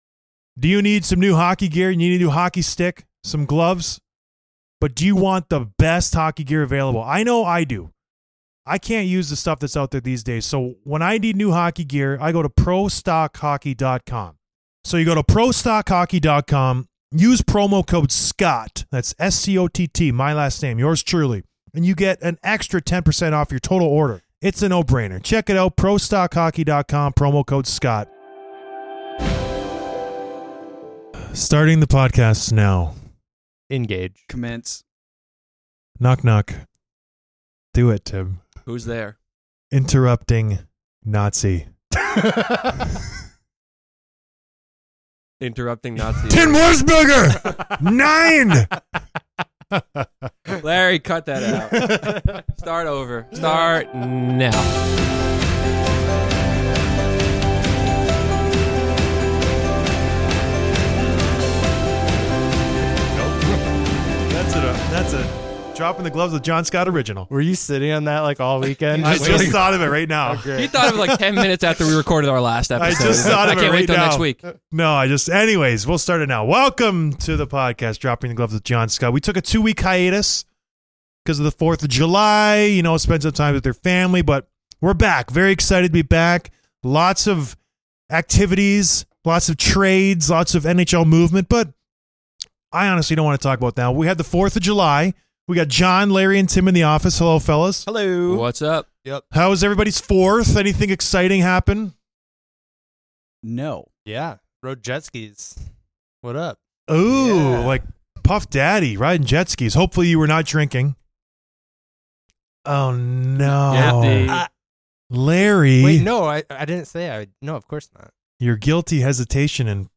Rasmus Ristolainen Calls In